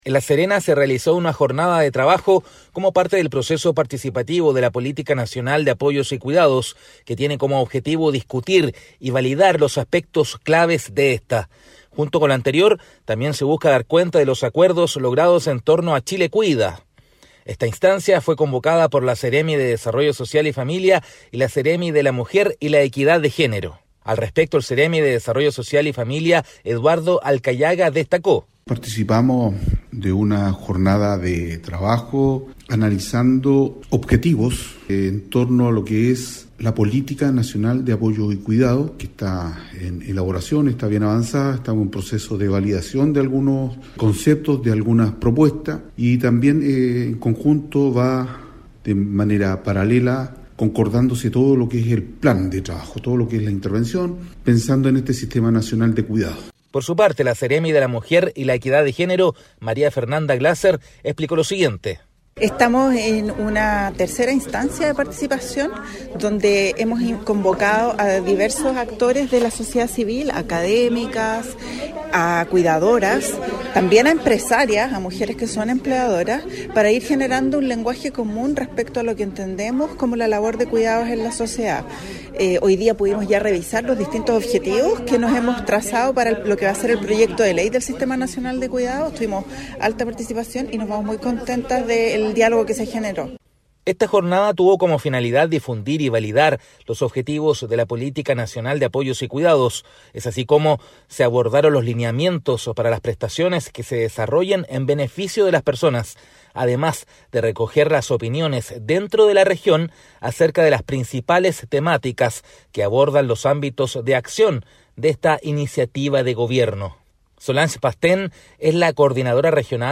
Despacho-radial_Personas-cuidadoras-valoran-proceso-participativo-de-la-Politica-Nacional-de-Apoyos-y-Cuidados.mp3